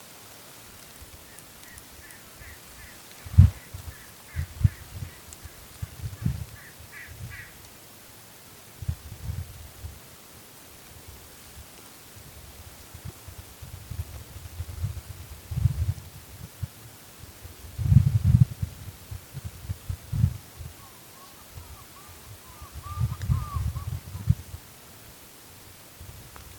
Becasina Gigante (Gallinago undulata)
Localidad o área protegida: Reserva Natural del Bosque Mbaracayú
Condición: Silvestre
Certeza: Vocalización Grabada